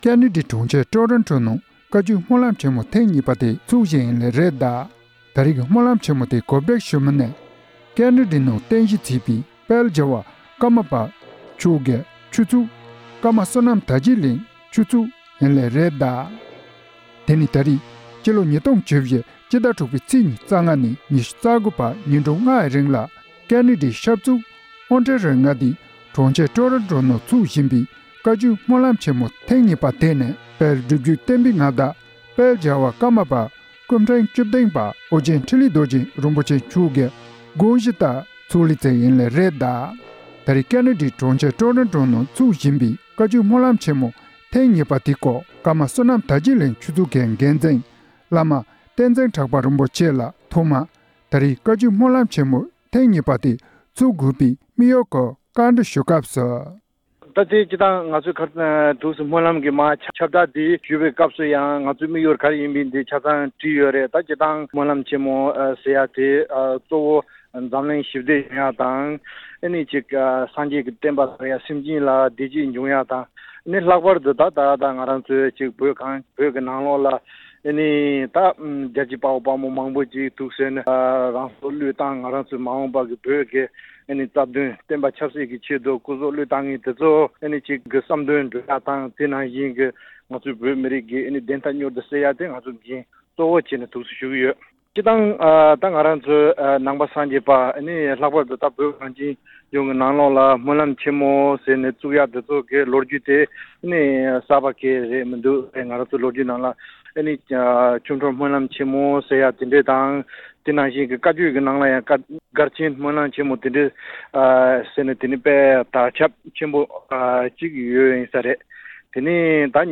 བཀའ་འདྲི་ཞུས་པ